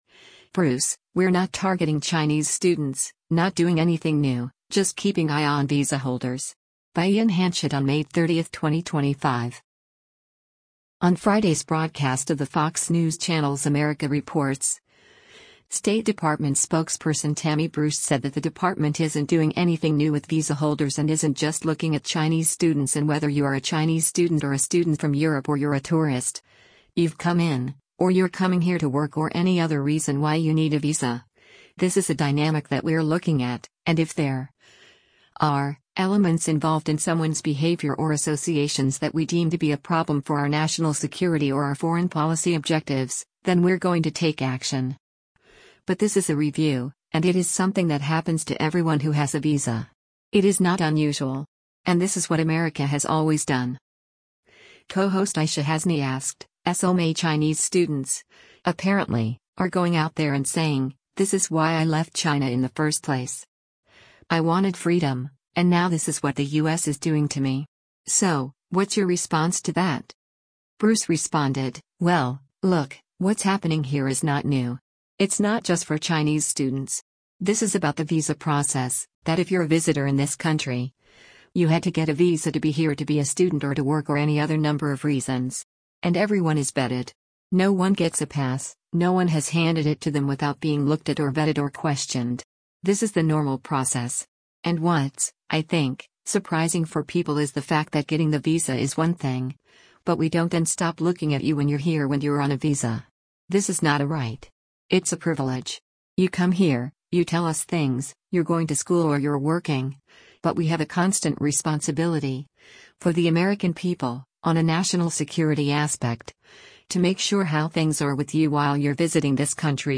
On Friday’s broadcast of the Fox News Channel’s “America Reports,” State Department Spokesperson Tammy Bruce said that the department isn’t doing anything new with visa holders and isn’t just looking at Chinese students and “whether you are a Chinese student or a student from Europe or you’re a tourist, you’ve come in, or you’re coming here to work or any other reason why you need a visa, this is a dynamic that we’re looking at, and if there [are] elements involved in someone’s behavior or associations that we deem to be a problem for our national security or our foreign policy objectives, then we’re going to take action.
Co-host Aishah Hasnie asked, “[S]ome Chinese students, apparently, are going out there and saying, this is why I left China in the first place.